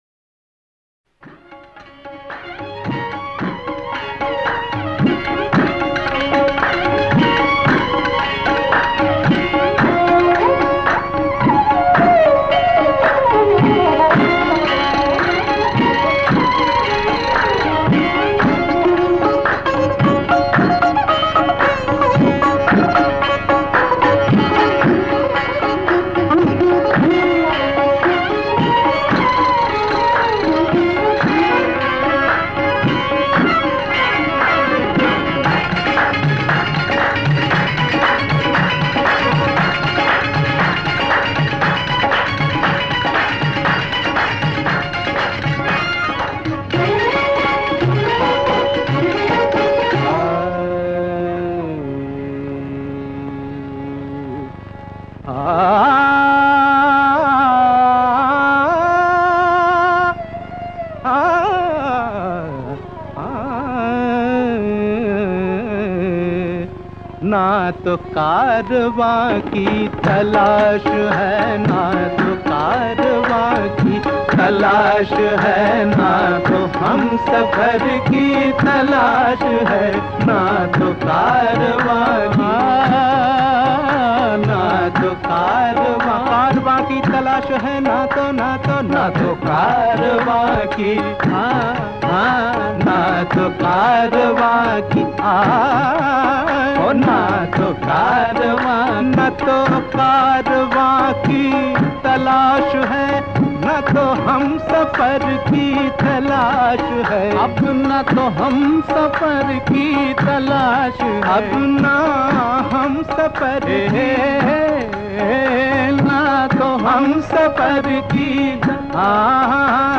Qawwalis From Old Films